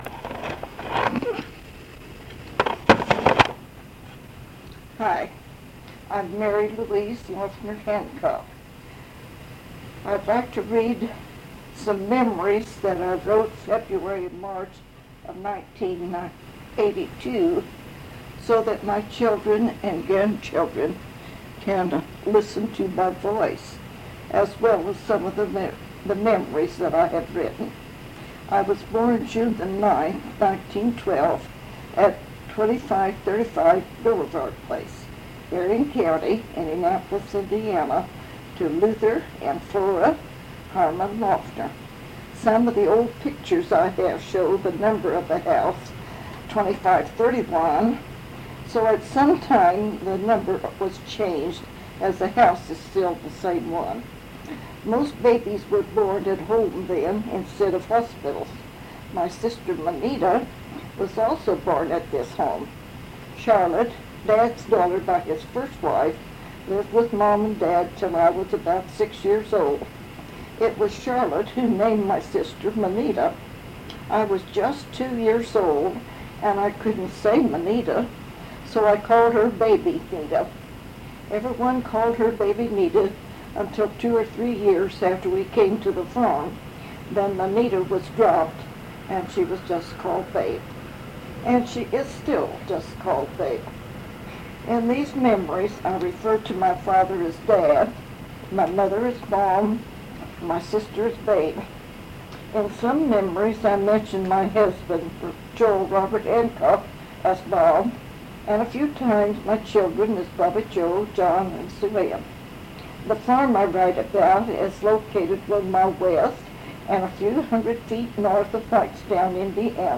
Interviews
Copied from audio cassette tape.